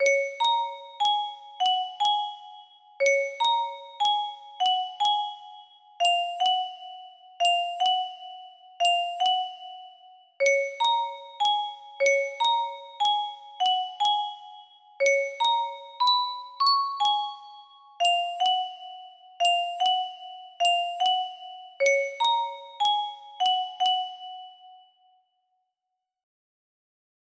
^.^ music box melody
Grand Illusions 30 (F scale)